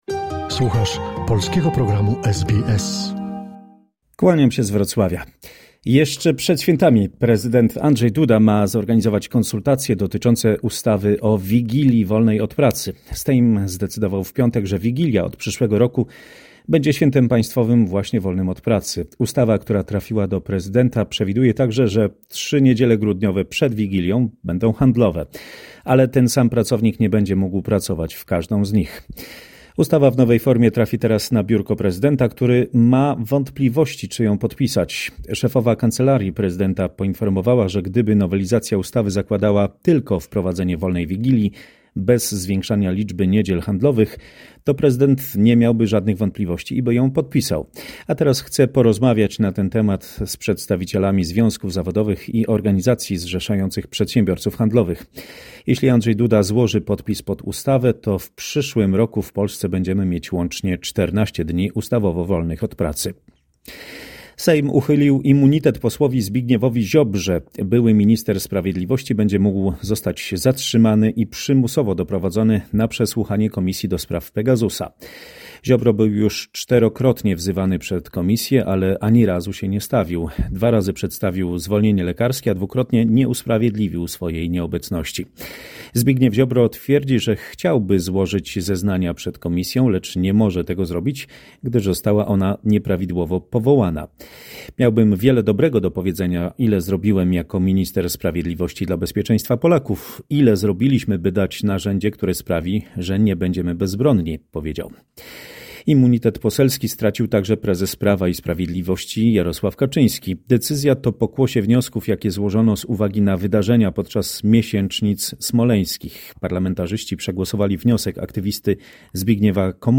usłyszymy w dzisiejszej korespondecji z Polski